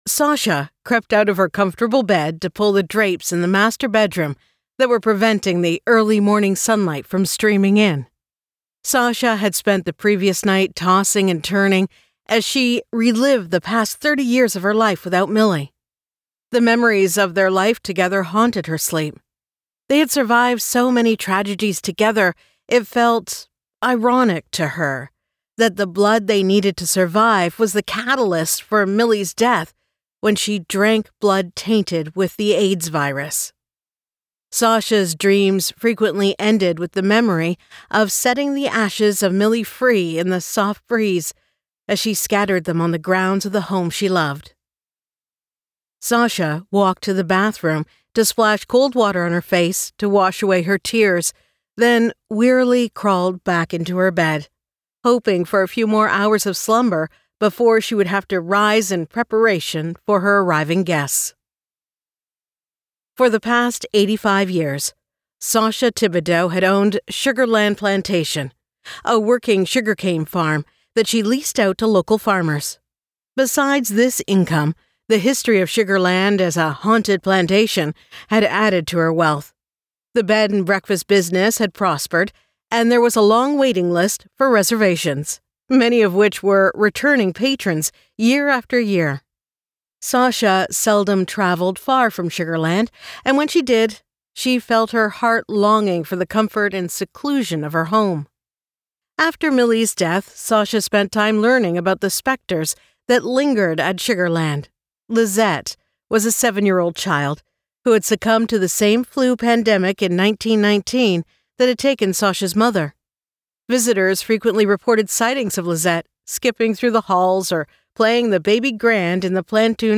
Bayou Justice by Ali Spooner Sasha Thibodaux Series, Book 2 [Audiobook]